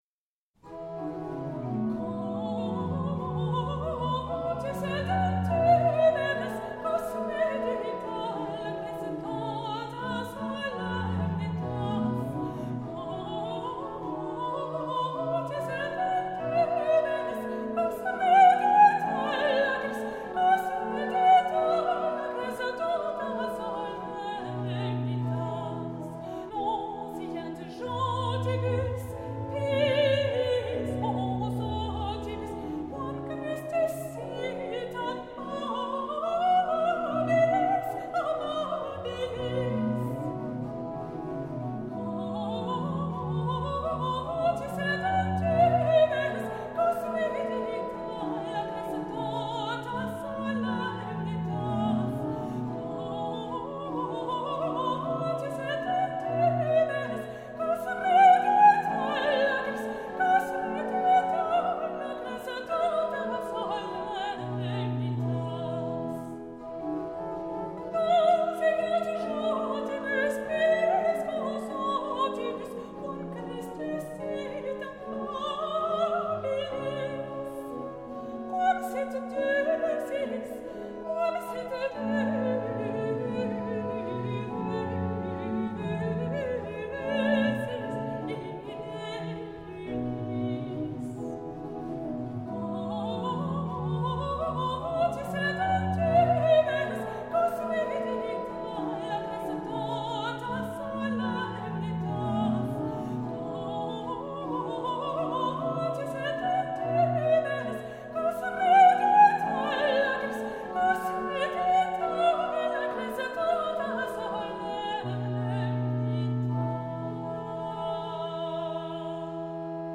Petit motet
~1600 (Baroque)